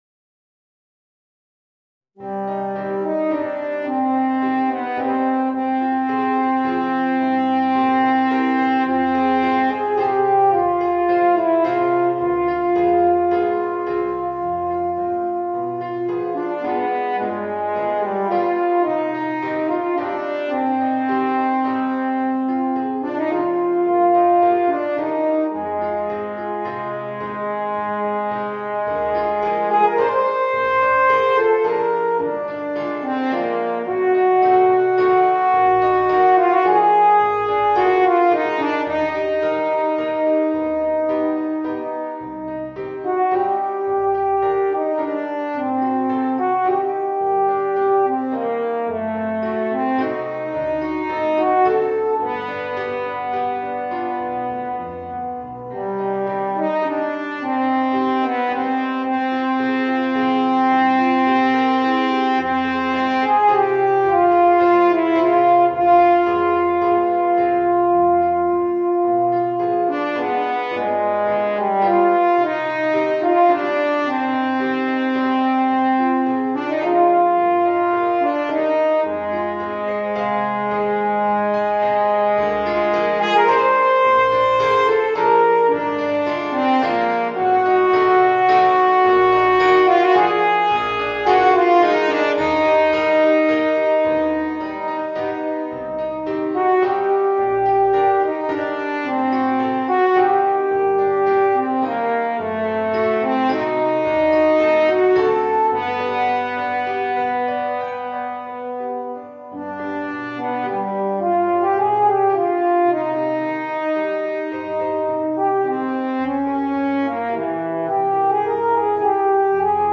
Voicing: Eb Horn and Piano